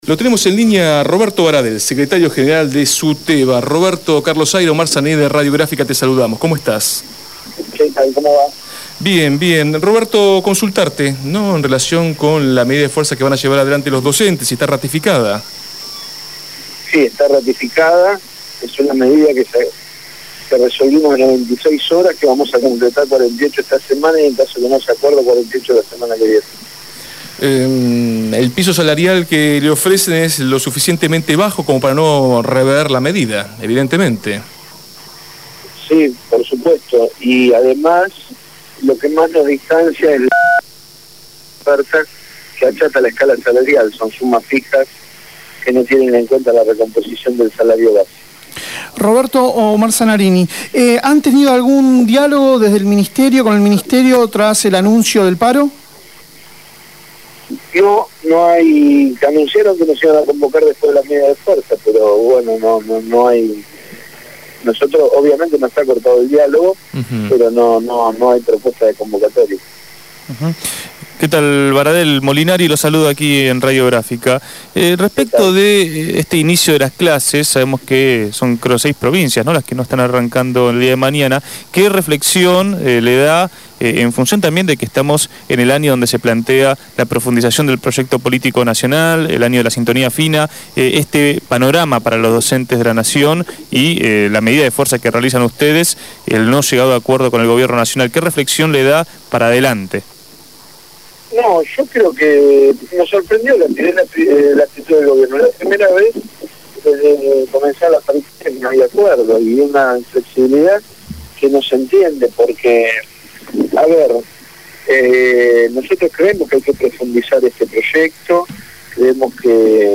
Roberto Baradel, Secretario General de SUTEBA, habló en Desde el Barrio sobre el paro docente en la Provincia de Buenos Aires el día de mañana y de la falta de acuerdo en las negociaciones de la paritaria nacional.